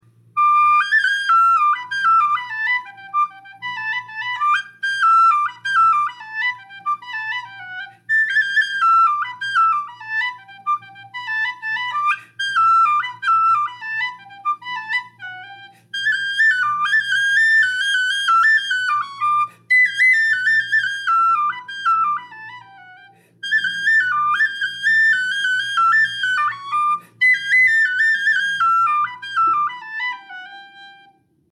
Number: #2 Key: High D Date completed: September 2023 Type: Tunable brass Volume: Average; would likely be heard in most sessions. Not on the quieter end; your housemates would hear you practice, but the sound is sweet in both octaves and they probably wouldn't mind.
Sound clip: Unknown jig: